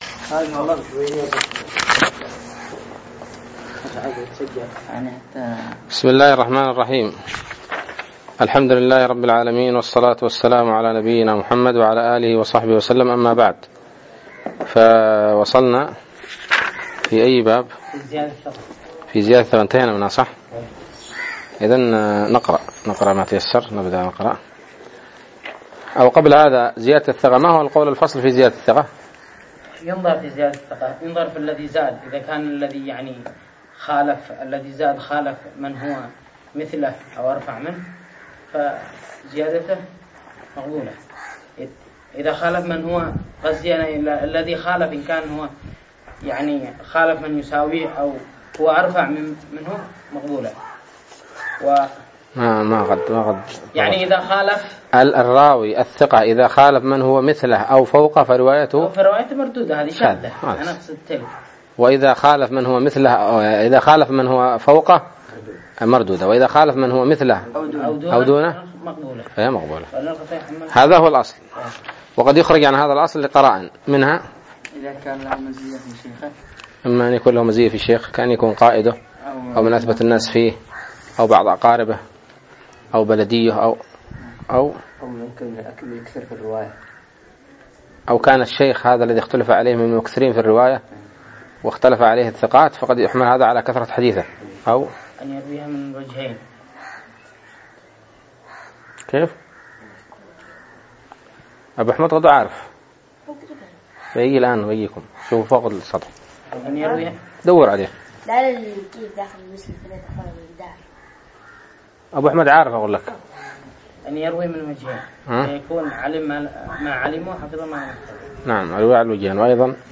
الدرس الخامس عشر من شرح نزهة النظر